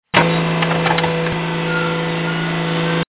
Elevator sound
Category: Sound FX   Right: Personal